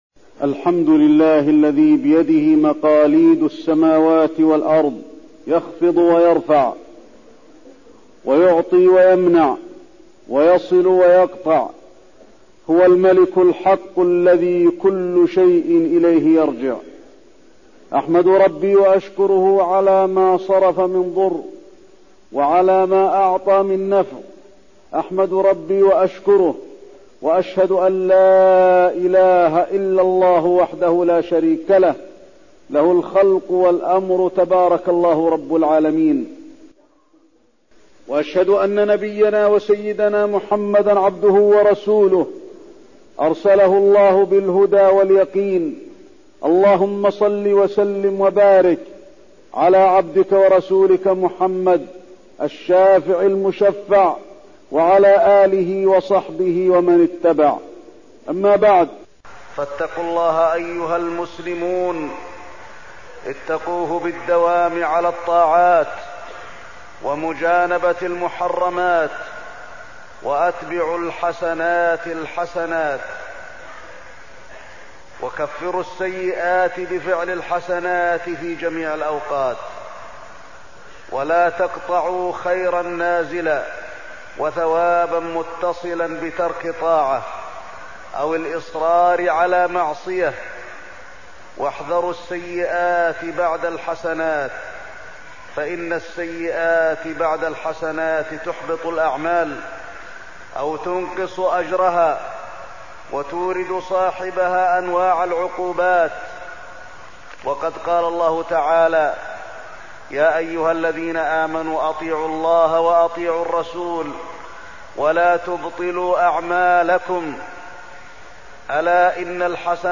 تاريخ النشر ٥ شوال ١٤١٦ هـ المكان: المسجد النبوي الشيخ: فضيلة الشيخ د. علي بن عبدالرحمن الحذيفي فضيلة الشيخ د. علي بن عبدالرحمن الحذيفي الحث على مواصلة العبادة بعد رمضان